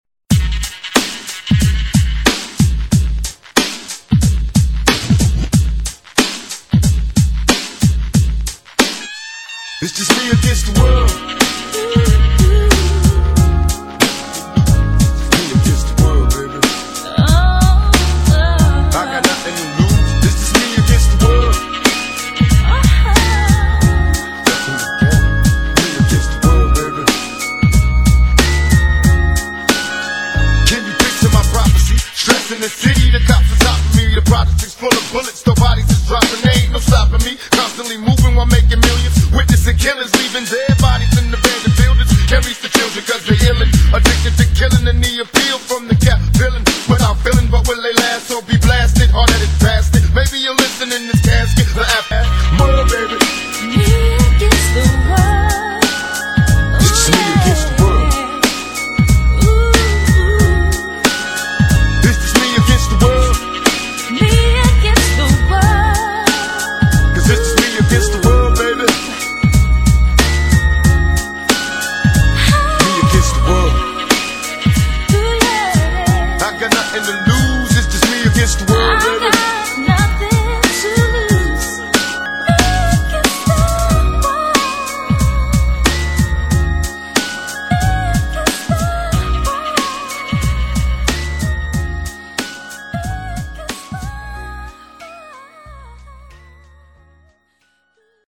BPM137--1
Audio QualityPerfect (High Quality)